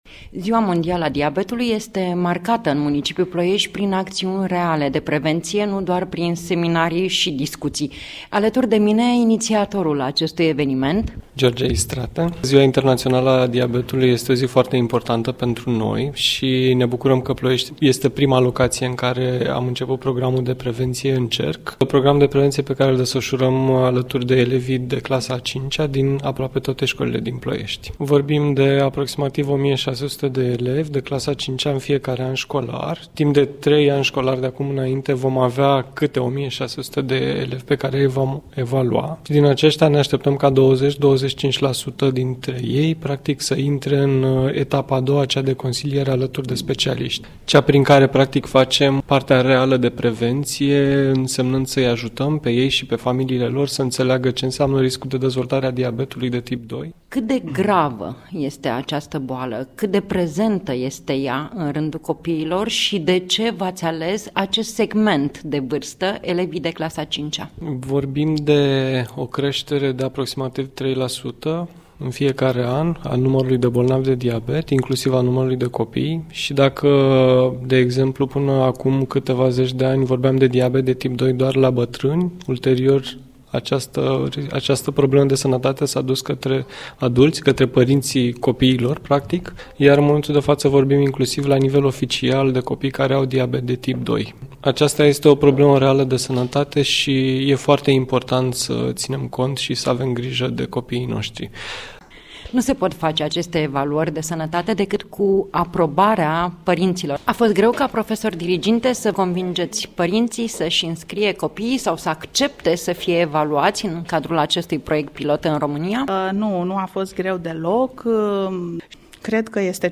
Reportaj radio difuzat la Radio România Actualități în cadrul emisiunilor „Obiectiv România”, „La dispoziția dumneavoastră” și în cadrul jurnalului orei 08:00, în data de 14 noiembrie 2017, cu ocazia Zilei Mondiale a Diabetului.